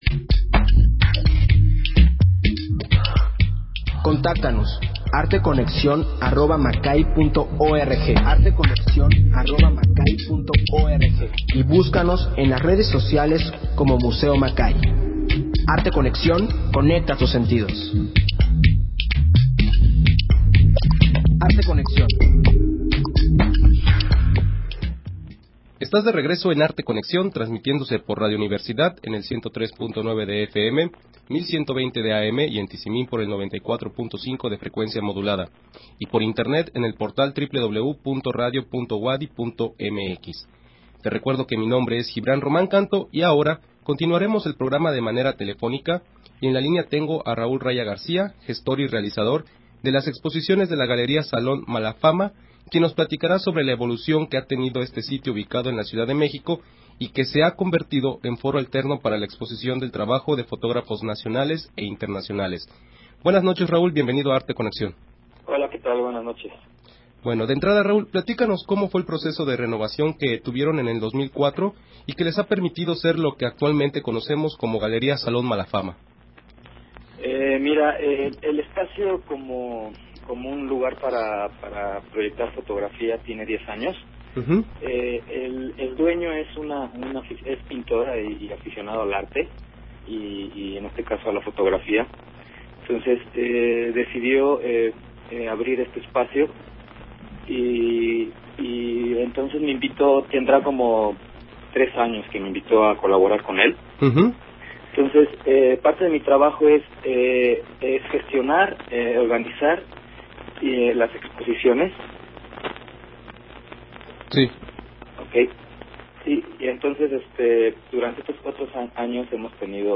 Emisión de Arte Conexión transmitida el 16 de febrero del 2017.